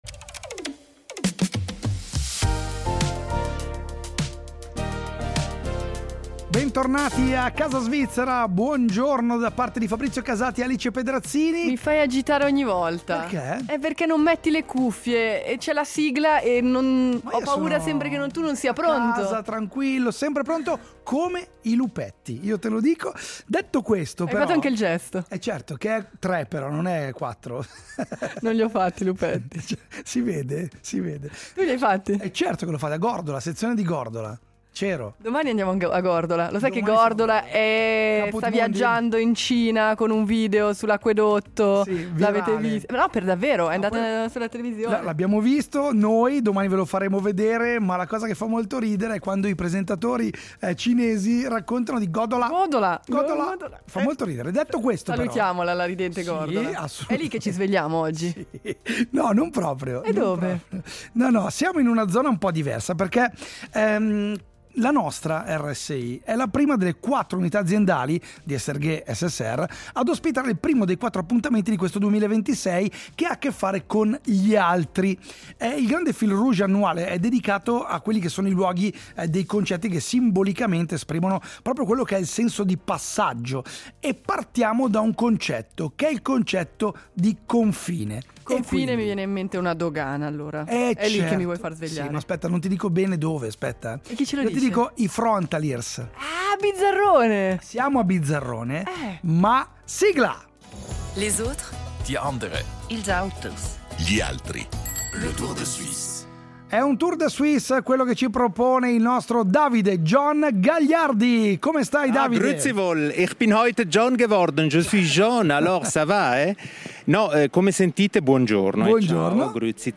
Siamo stati a Bizzarrone, alla dogana che ogni giorno attraversano migliaia di persone: frontalieri, doganieri, lavoratori, vite in movimento. Un luogo che non è solo un passaggio… ma un punto d’incontro, di equilibrio, di tensione e di possibilità.